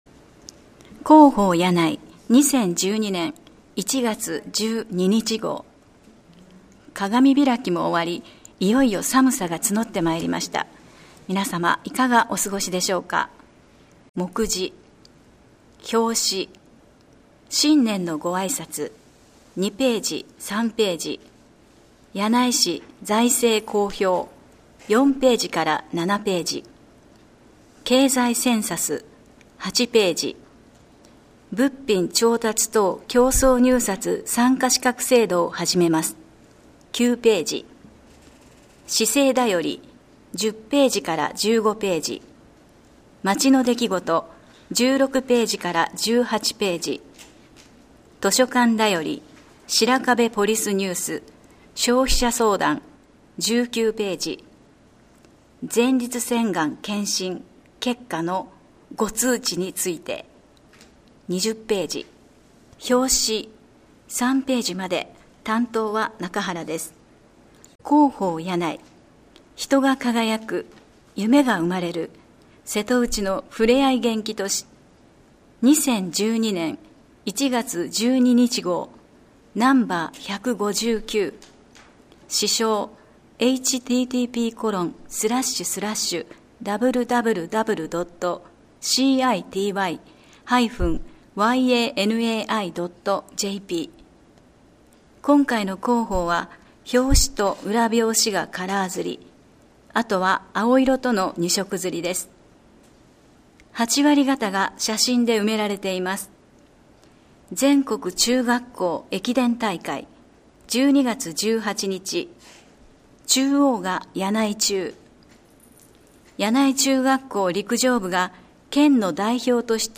声の広報（音訳版：発行後1週間程度で利用可能）はこちらから [mp3／47.93MB]